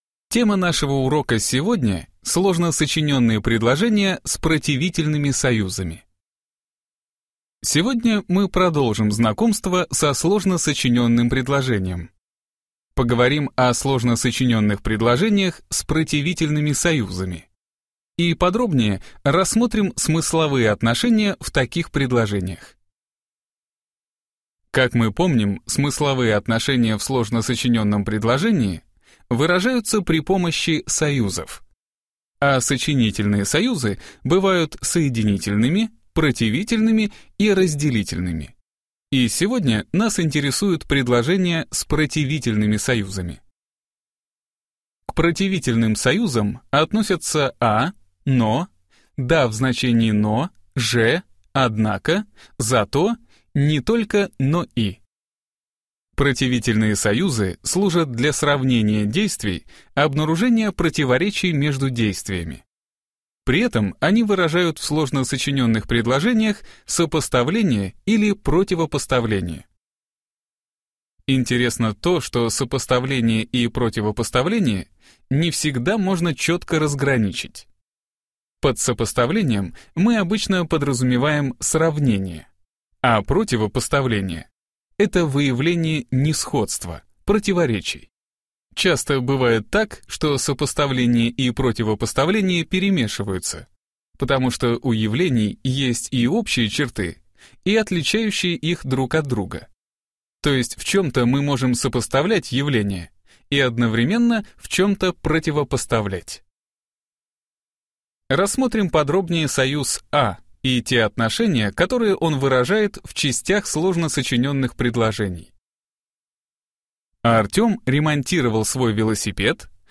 Аудиоуроки по русскому языку